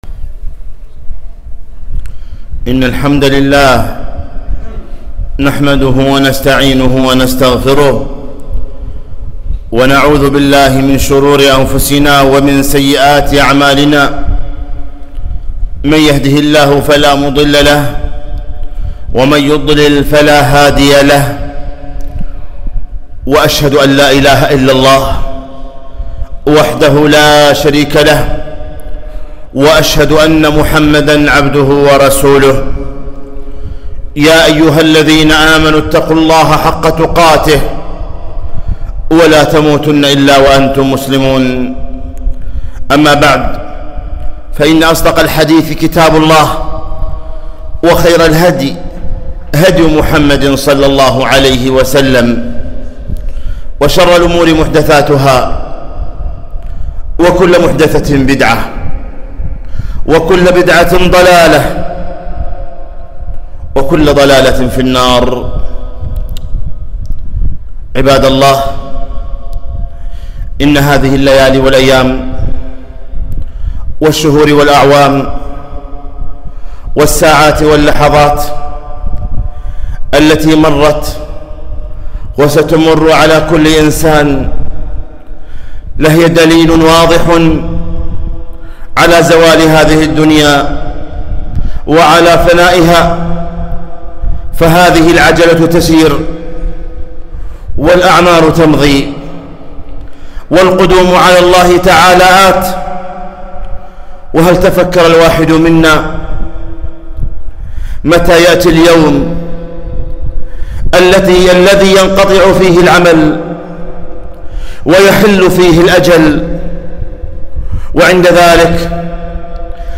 خطبة - اغتنام الأوقات بالباقيات الصالحات 5-8-1442